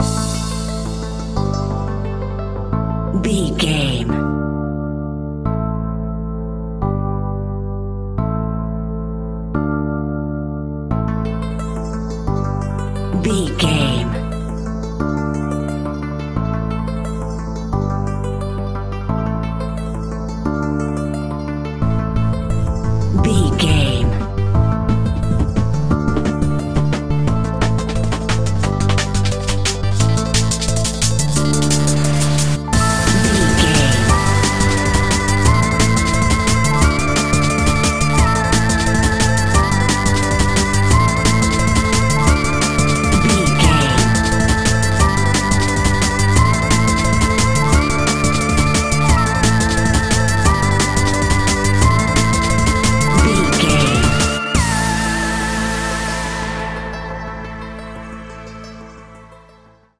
Aeolian/Minor
Fast
aggressive
dark
driving
energetic
groovy
drum machine
synthesiser
electronic
sub bass
synth leads